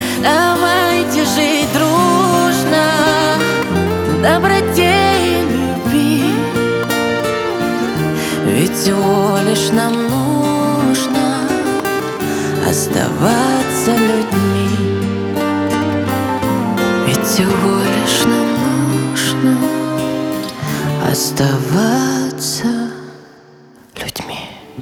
эстрада
чувственные , гитара